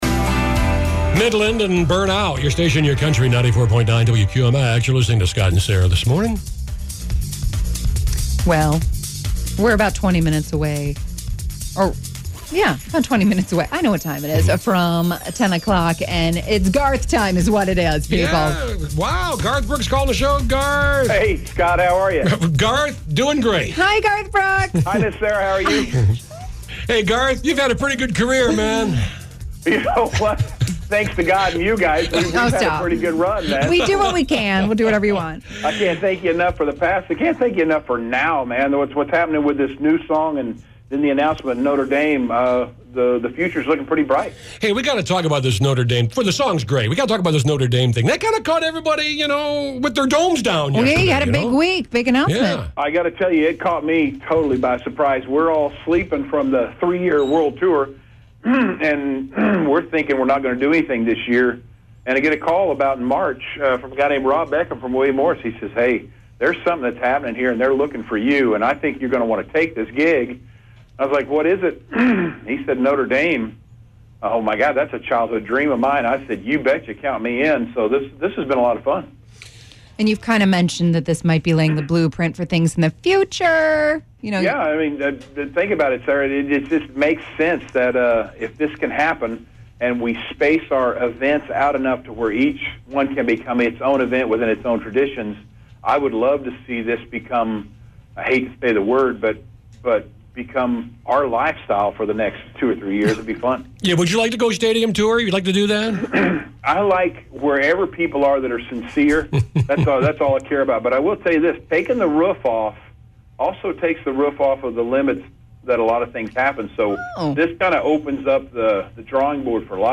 Garth Brooks Calls the Morning Show
INTERVIEW
Garth-Interview.mp3